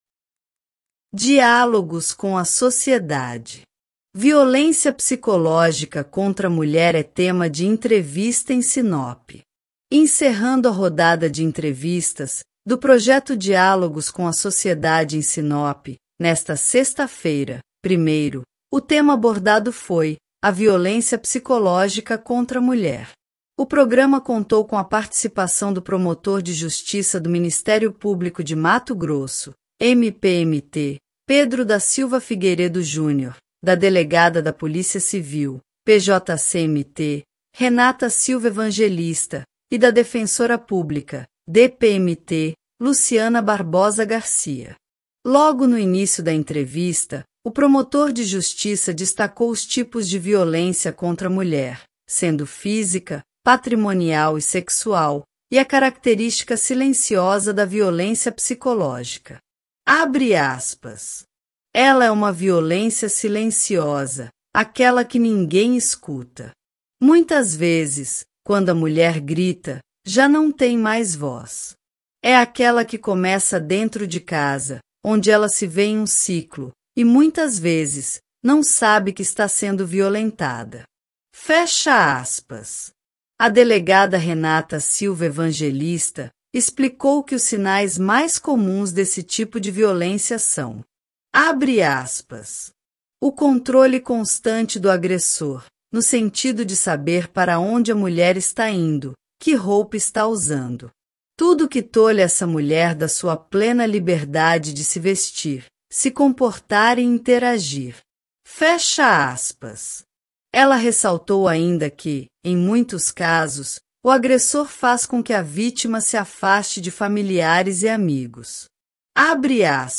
Violência psicológica contra a mulher é tema de entrevista em Sinop
Violência psicológica contra a mulher é tema de entrevista em Sinope_ ‐ .mp3